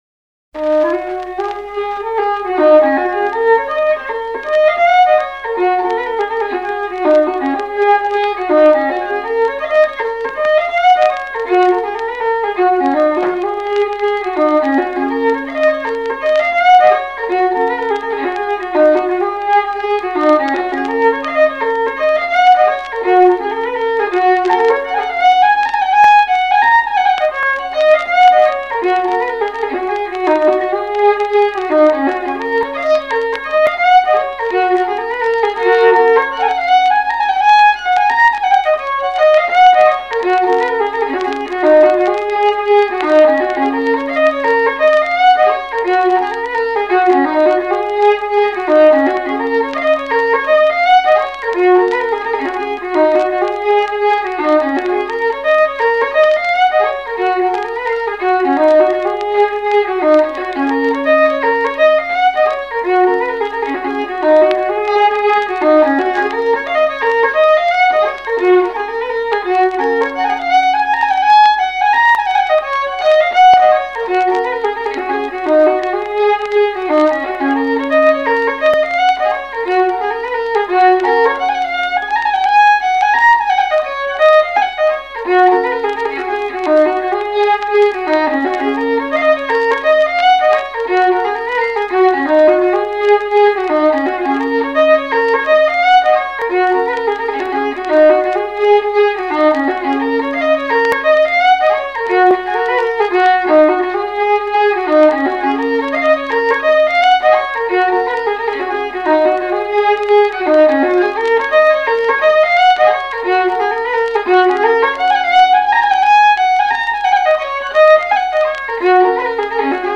fiddle
A couple of sultry hornpipes